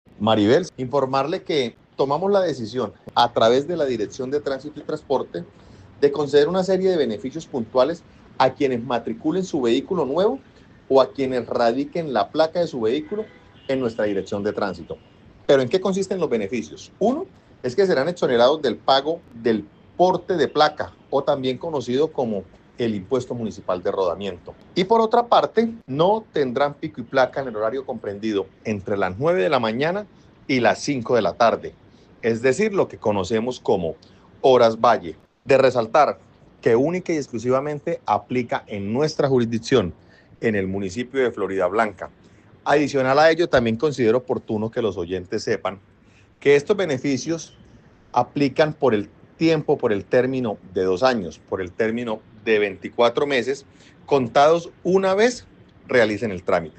José Fernando Sánchez, Alcalde de Floridablanca